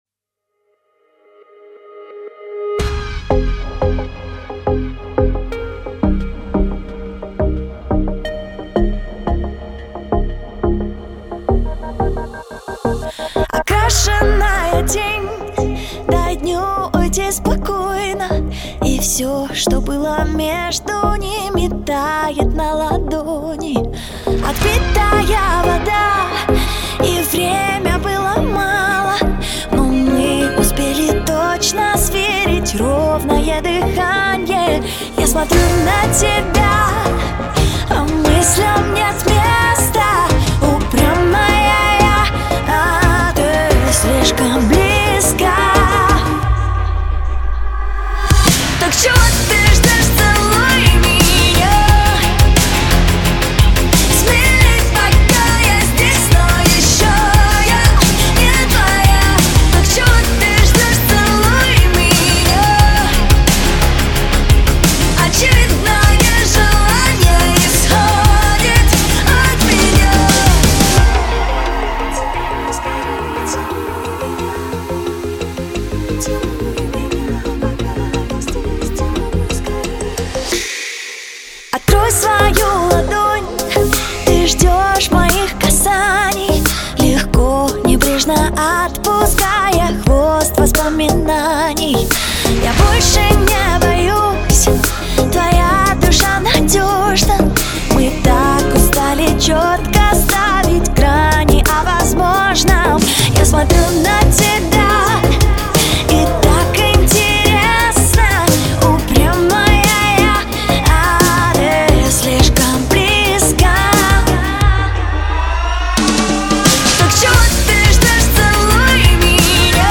Главная » Файлы » Поп Музыка Категория